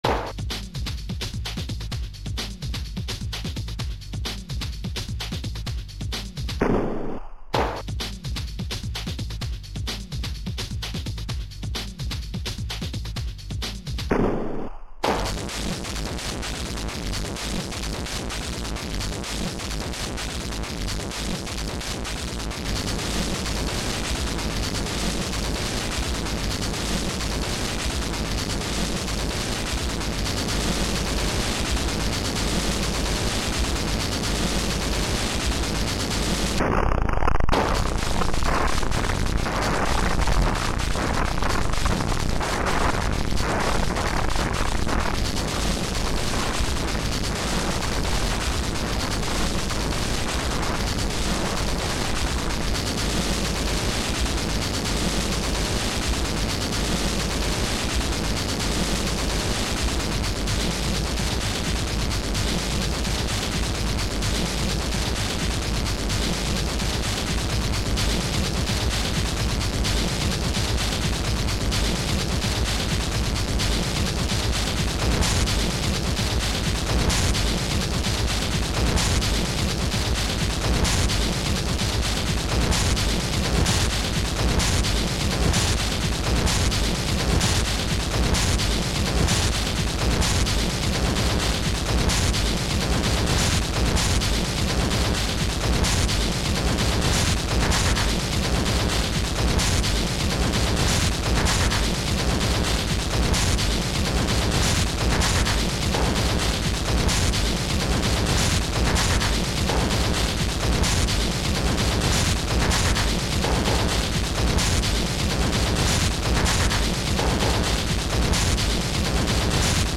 File under: Ambient / Industrial / Harsh Electronics
more layered and rhythmically structured